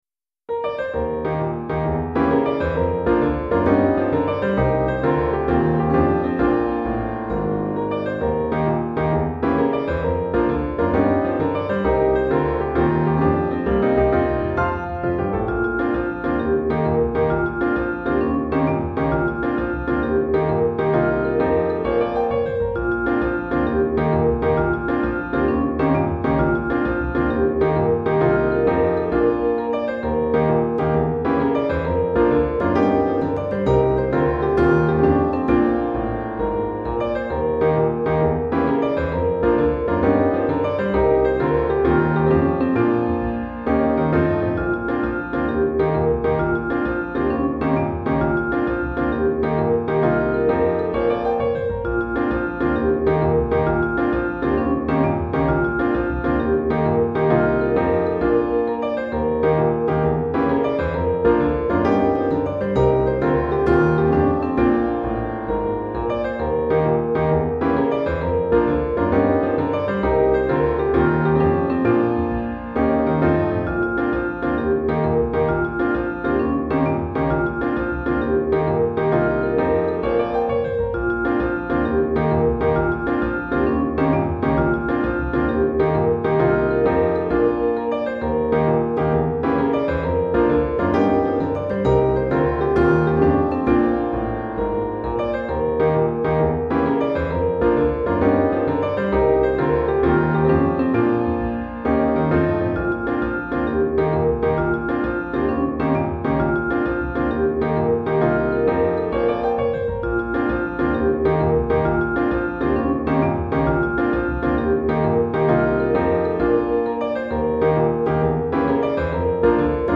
Chorale d'Enfants (10 à 12 ans) et Piano